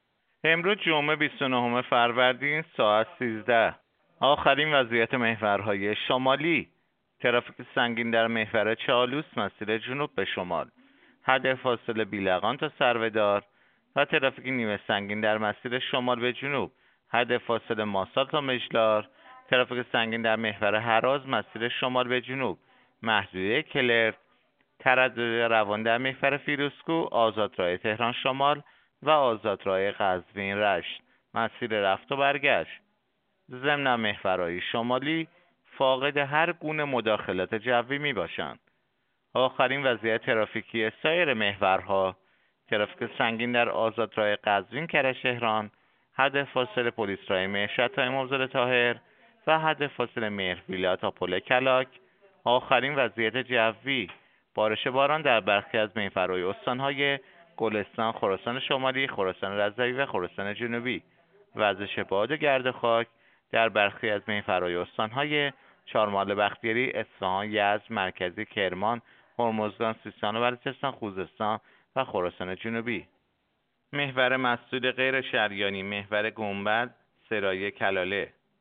گزارش رادیو اینترنتی از آخرین وضعیت ترافیکی جاده‌ها ساعت ۱۳ بیست و نهم فروردین؛